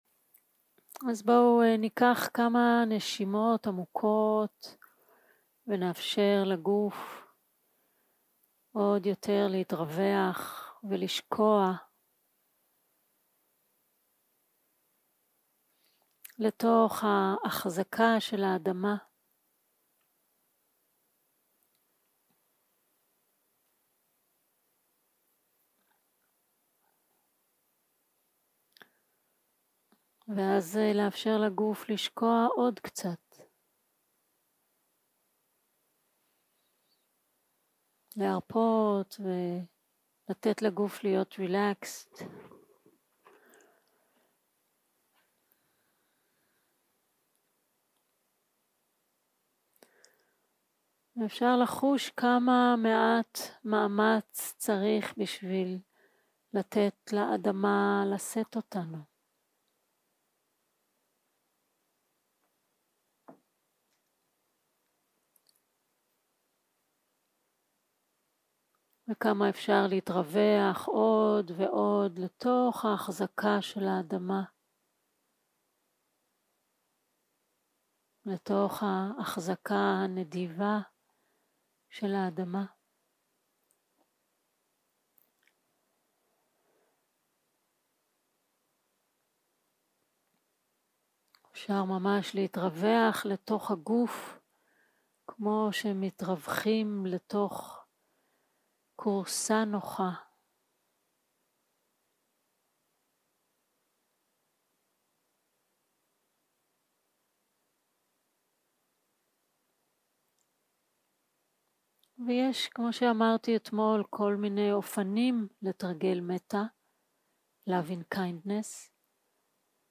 יום 3 - הקלטה 8 - ערב - מדיטציה מונחית - מטא
Dharma type: Guided meditation שפת ההקלטה